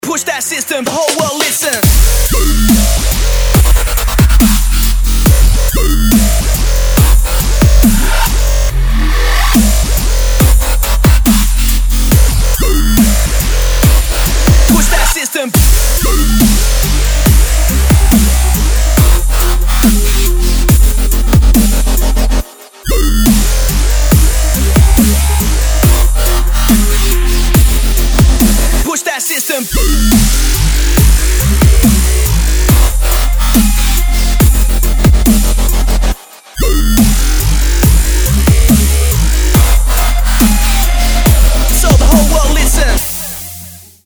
Новый живенький звучок в жанре Дабстеп!